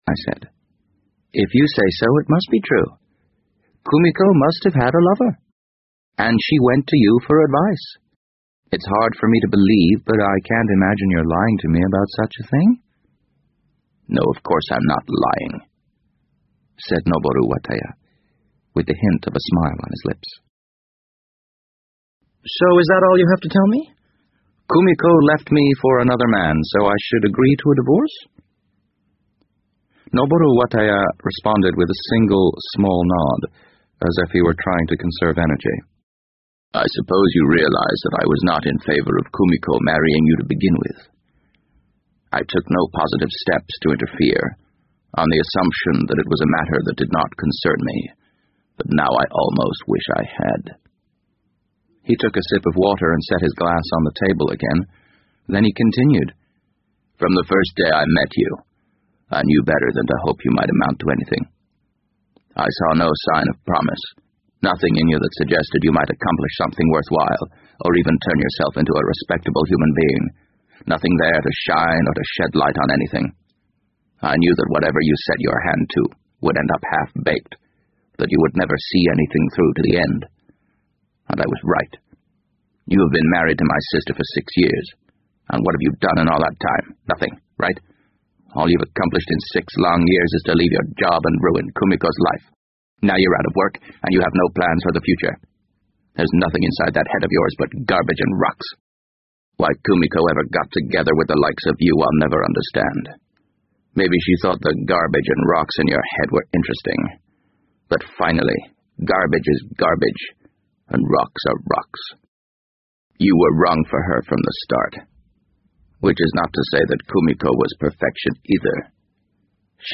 BBC英文广播剧在线听 The Wind Up Bird 005 - 19 听力文件下载—在线英语听力室